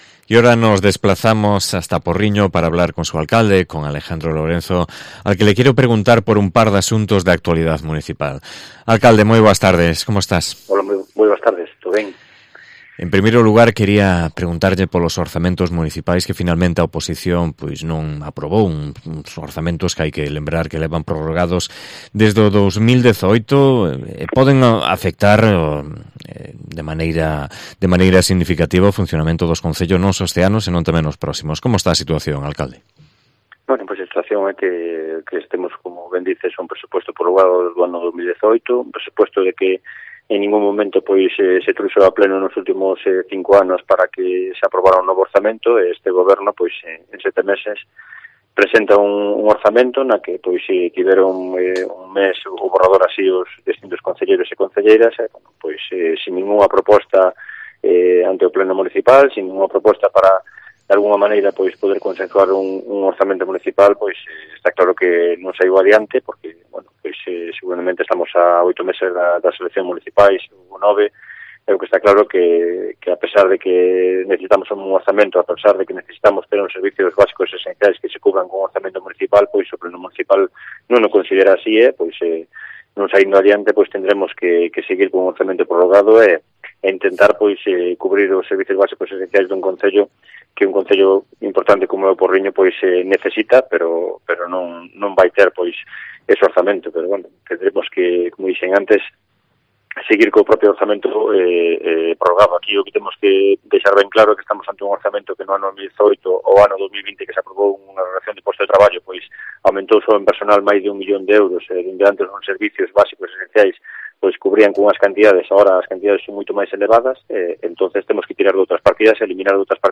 Entrevista con Alejandro Lorenzo, alcalde de Porriño